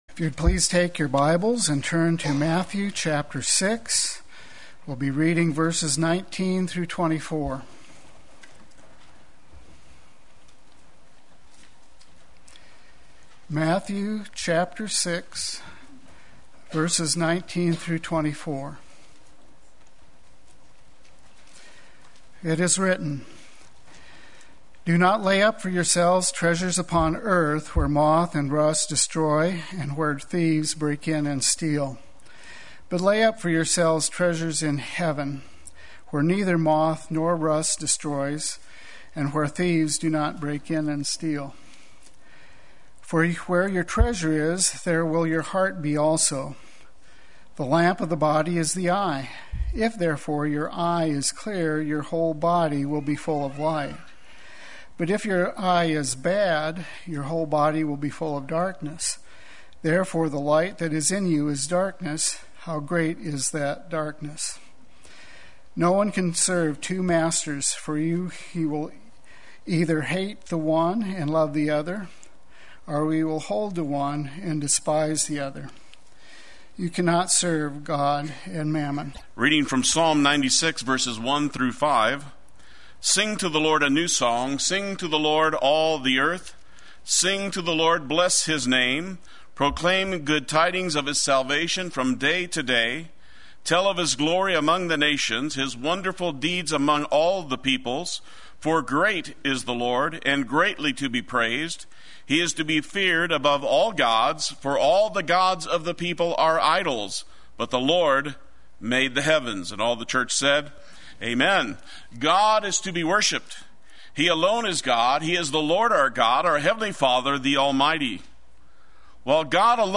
Play Sermon Get HCF Teaching Automatically.
Treasuring God Sunday Worship